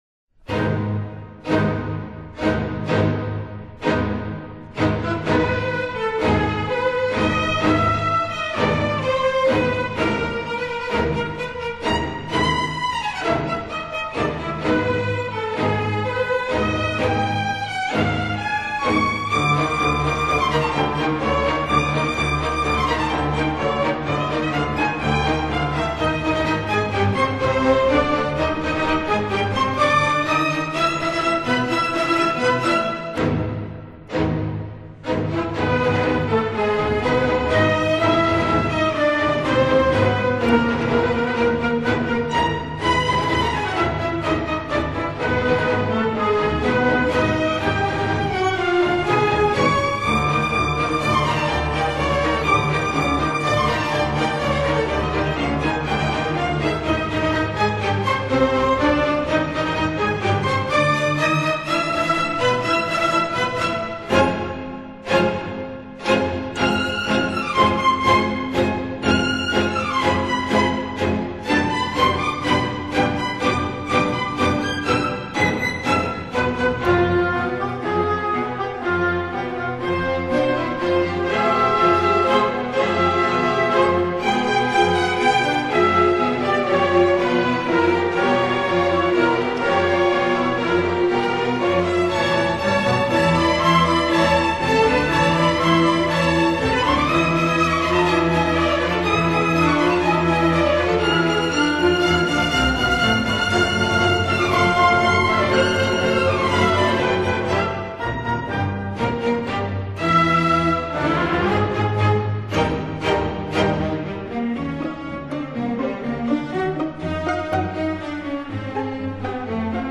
分辑：CD12-CD13  室内交响曲